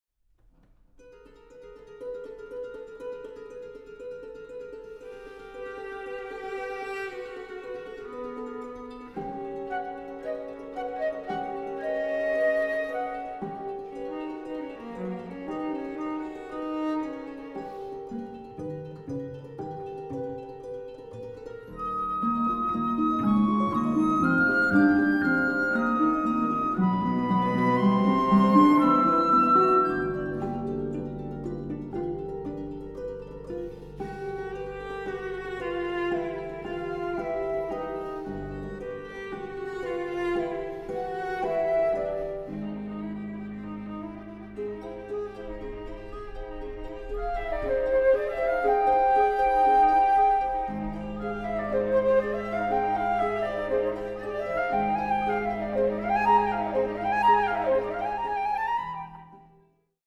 Flöte
Violoncello
Harfe